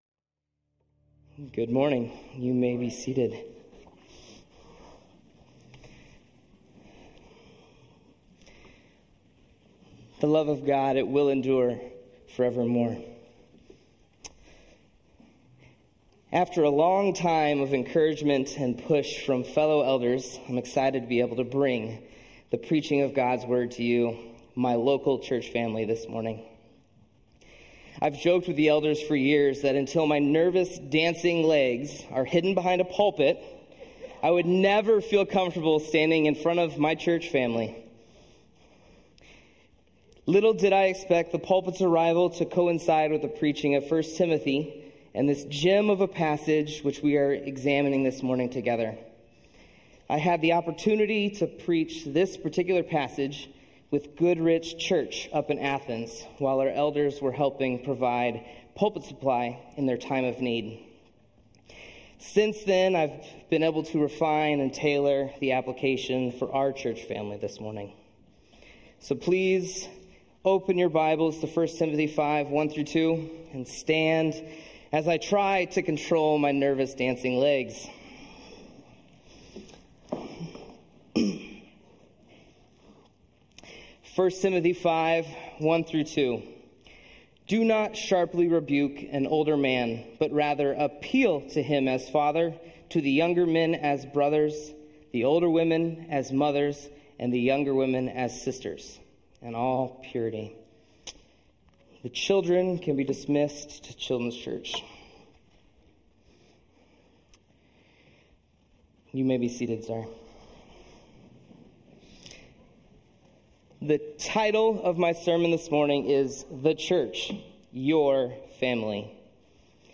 Sermons Archive - Page 4 of 186 - Immanuel Baptist Church - Wausau, WI